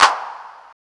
CLAP     5-L.wav